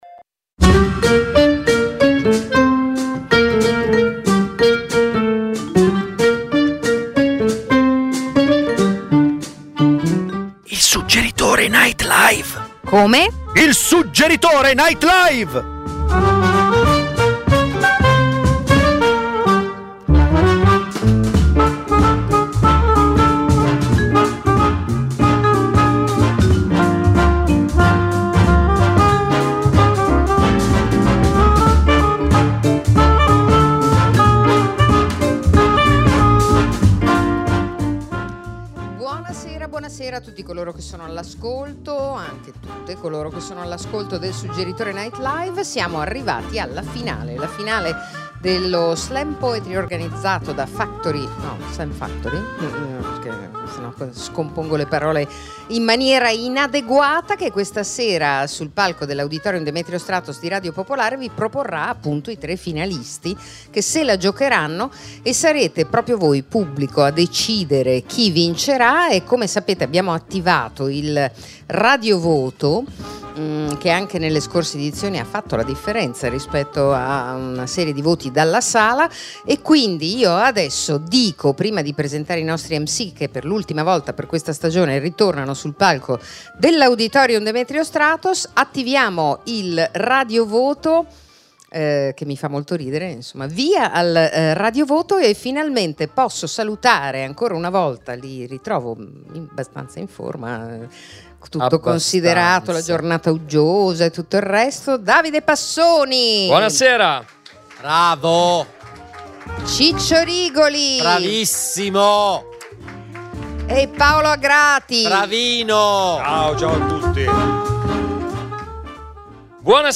Il Suggeritore Night Live, ogni lunedì dalle 21:30 alle 22:30 dall’Auditorium Demetrio Stratos, è un night talk-show con ospiti dello spettacolo dal vivo che raccontano e mostrano estratti dei loro lavori. Gli ascoltatori possono partecipare come pubblico in studio a partire dalle 21.00. E spesso, il Suggeritore NL vi propone serate speciali di stand up, slam poetry, letture di drammaturgia contemporanea, imprò teatrale.